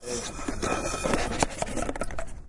描述：这是一段关于某人喝完自来水杯中最后一点健怡百事可乐和冰块的录音。 我是在斯坦福大学的Coho餐厅用Roland Edirol录制的。